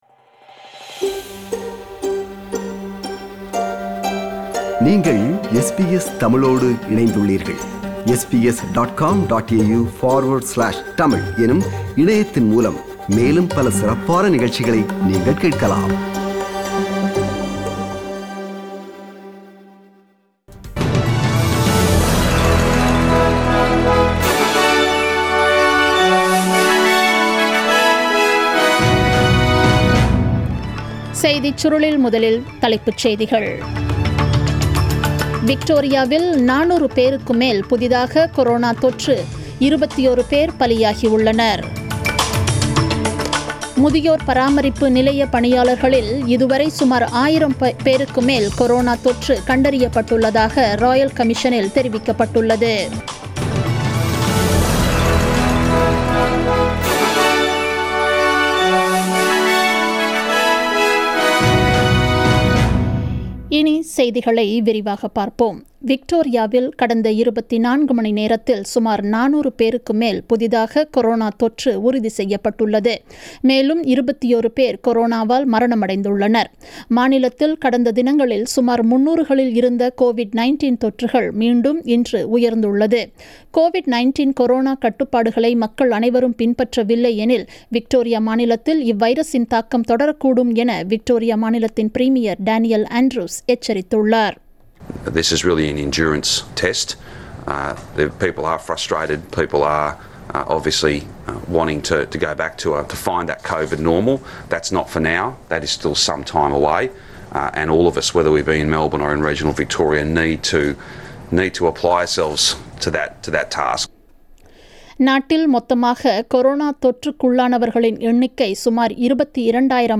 The news bulletin broadcasted on 12 August 2020 at 8pm.